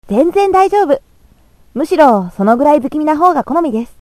音声素材
声職人さん方から提供された音声素材です。